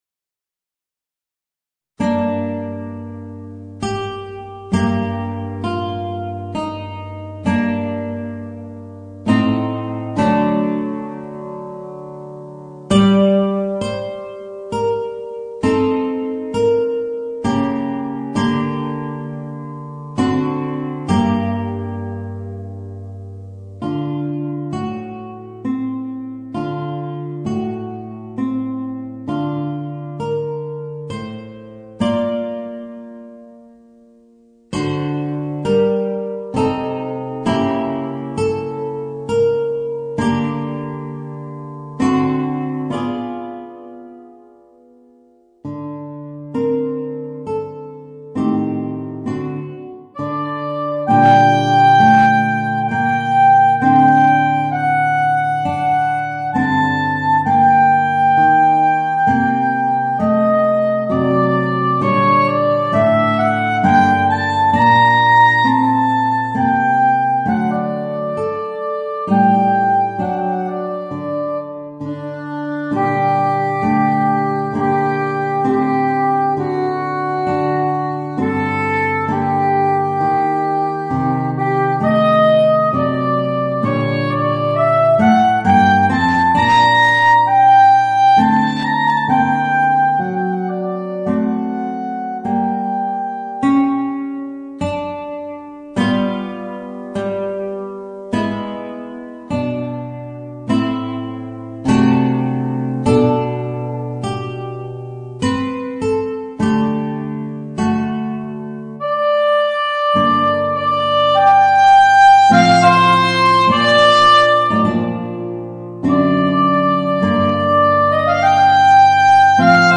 Voicing: Guitar and Soprano Saxophone